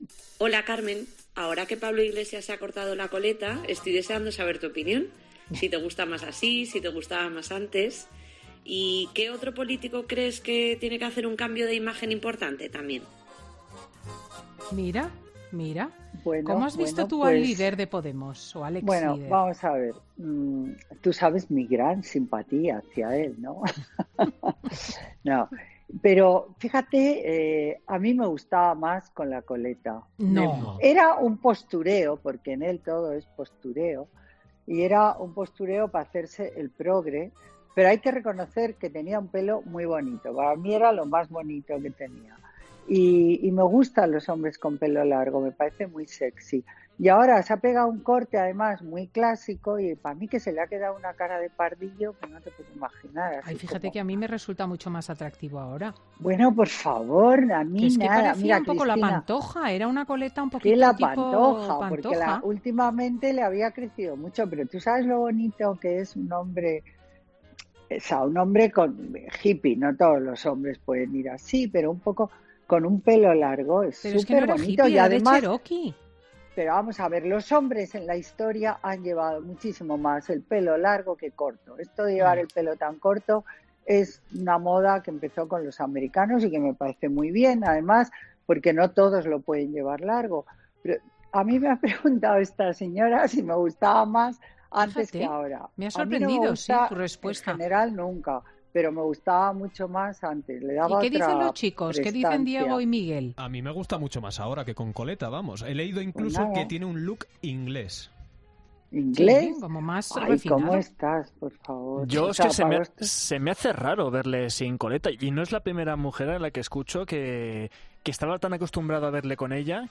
La socialité comenta en COPE el aspecto físico de los políticos del Congreso a raíz de que Iglesias se haya cortado la coleta
Algo en lo que la presentadora, Cristina López Schlichting, parecía estar de acuerdo: “Para empezar Santi es el doble, tiene un cuerpo atlético de gimnasio, absolutamente sexy, que no tenía entonces para nada”.